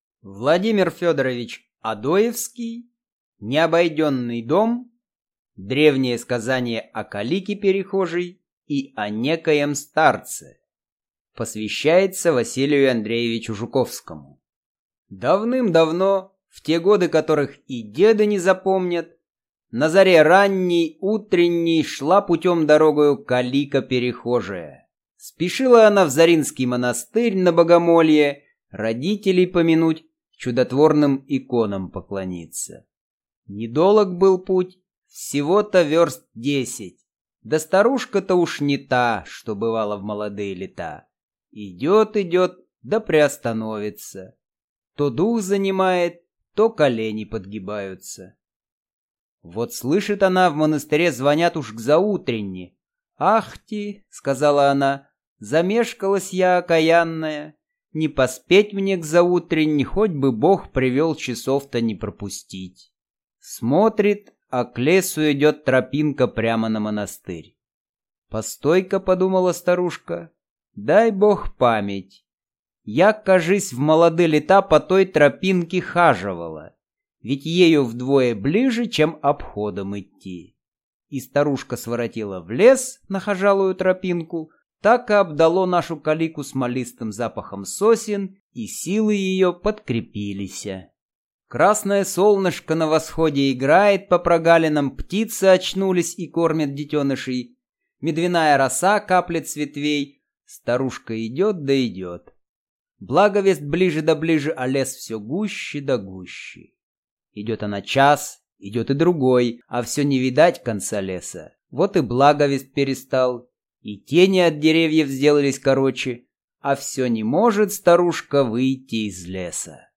Аудиокнига Необойденный дом | Библиотека аудиокниг